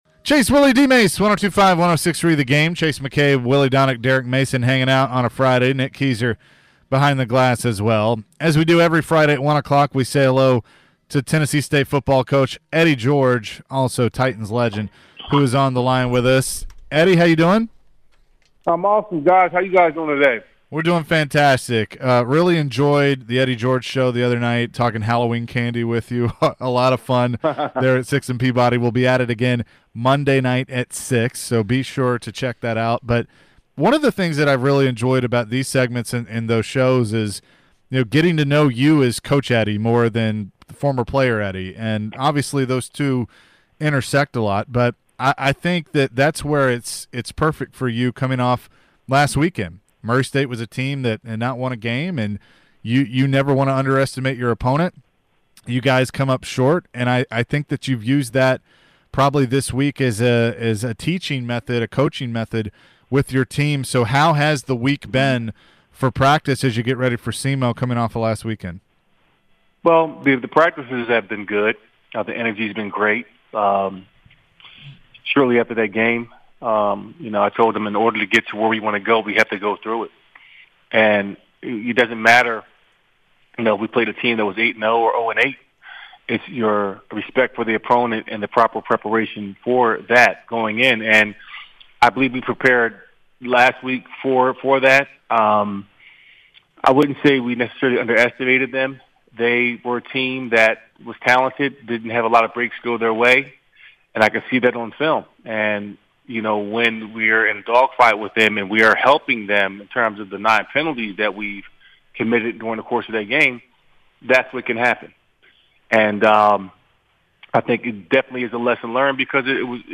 Eddie George interview (11-4-22)